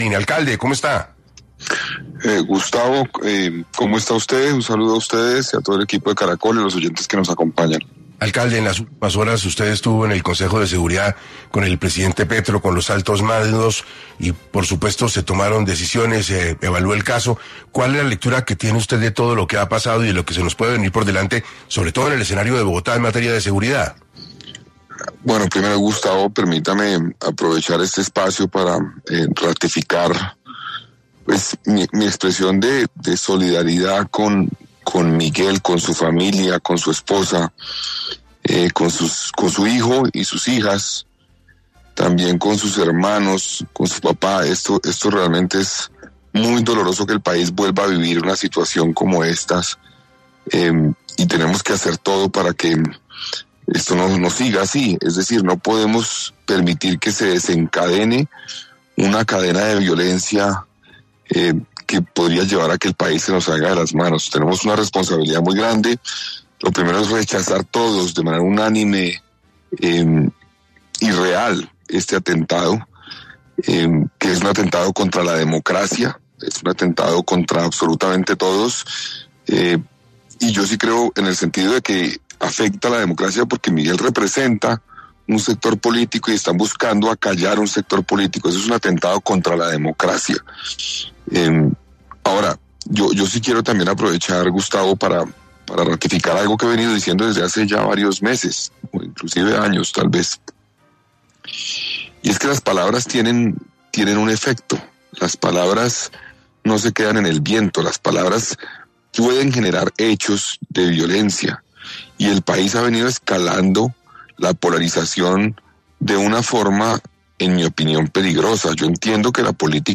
El mandatario local inició la entrevista expresando su solidaridad con el político, su familia y seres queridos, calificando el atentado como doloroso y un atentado contra la democracia, pues busca silenciar un sector político.